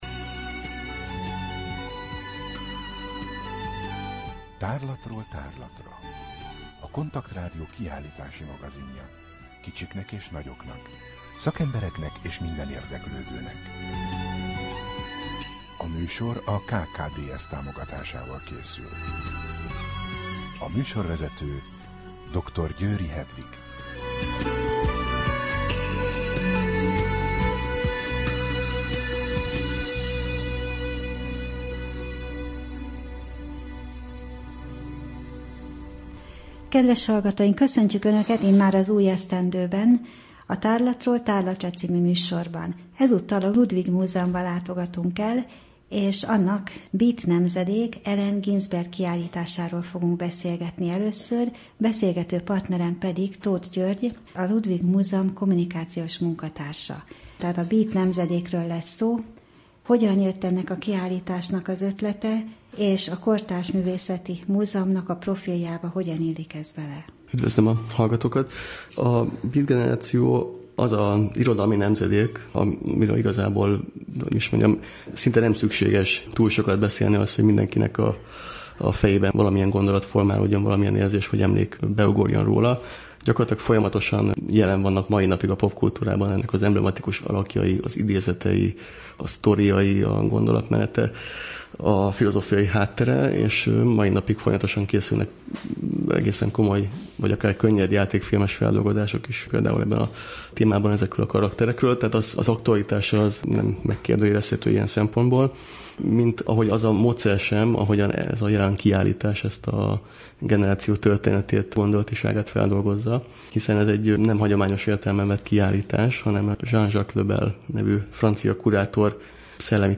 Rádió: Tárlatról tárlatra Adás dátuma: 2014, January 6 Tárlatról tárlatra / KONTAKT Rádió (87,6 MHz) 2014 január 6. A műsor felépítése: I. Kaleidoszkóp / kiállítási hírek II.